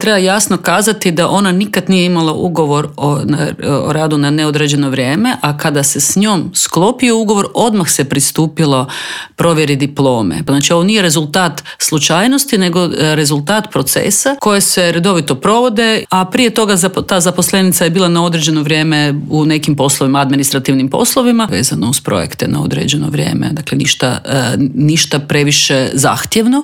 ZAGREB - Gostujući u Intervjuu tjedna Media servisa ministrica znanosti i obrazovanja Blaženka Divjak osvrnula se na slučaj zaposlenice Ministarstva koja je krivotvorila diplomu s Hrvatskih studija i zaradila prijavu DORH-u i otkaz kao i svi nadležni.